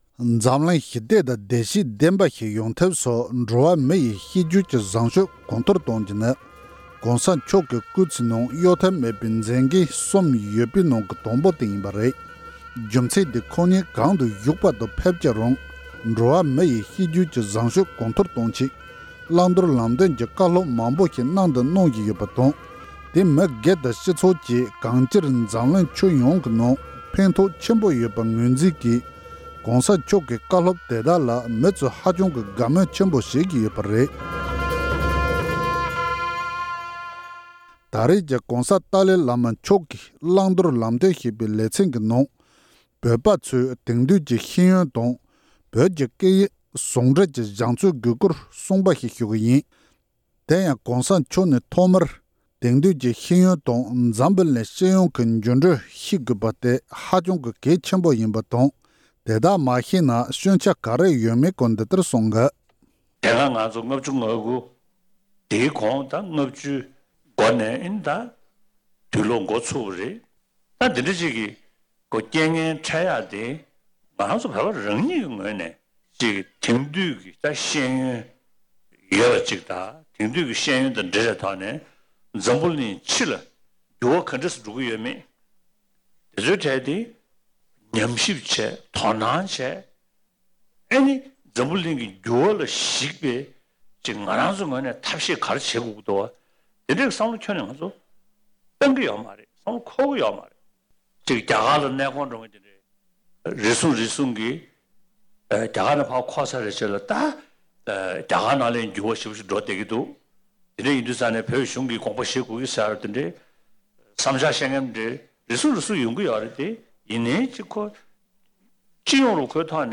དེང་དུས་ཀྱི་ཤེས་ཡོན་དང་མཉམ་བོད་སྐད་ཡང་གལ་ཆེ། ༸གོང་ས་༸སྐྱབས་མགོན་ཆེན་པོ་མཆོག་གིས་ལ་དྭགས་ཀྱི་ཟངས་དཀར་ཁུལ་དུ་དད་ལྡན་མི་མང་ལ་བཀའ་ཆོས་སྩོལ་བཞིན་པ།